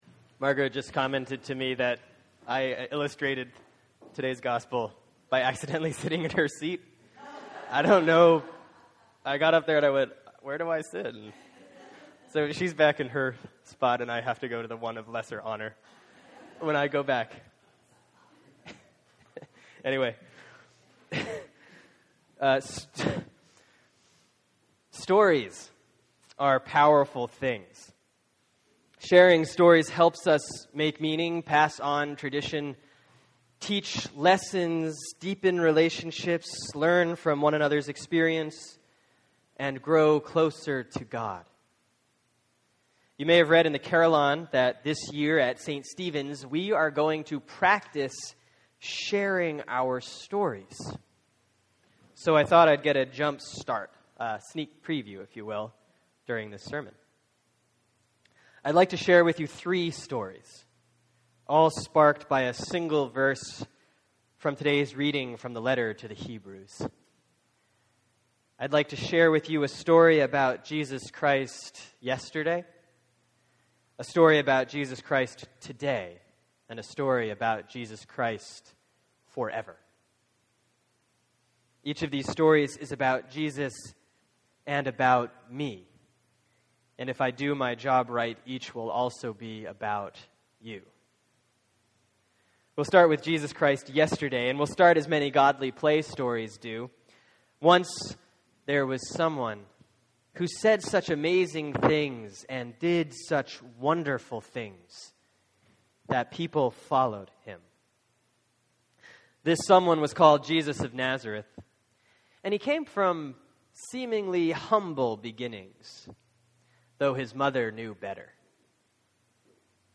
(Sermon for Sunday, September 1, 2013 || Proper 17C || Hebrews 13:1-8, 15-16)